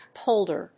/ˈpoldɝ(米国英語), ˈpəʊldɜ:(英国英語)/